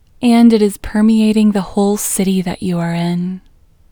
WHOLENESS English Female 9